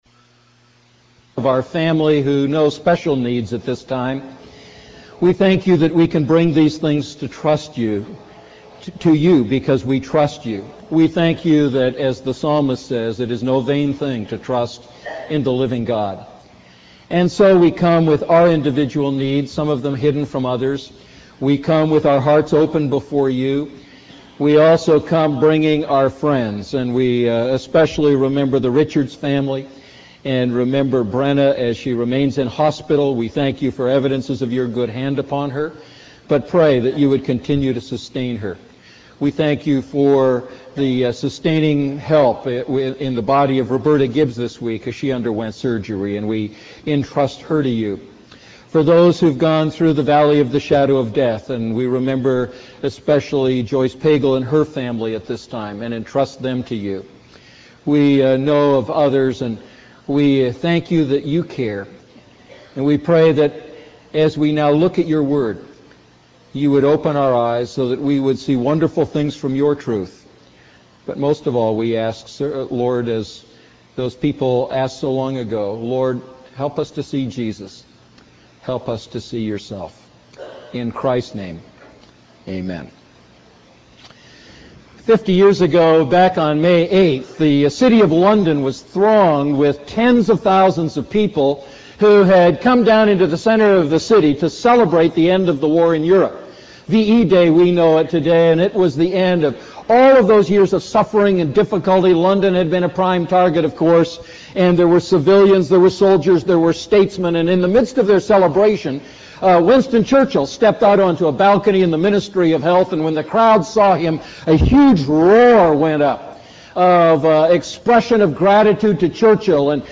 A message from the series "Living Inside Out."